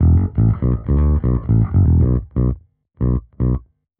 Index of /musicradar/dusty-funk-samples/Bass/120bpm
DF_JaBass_120-B.wav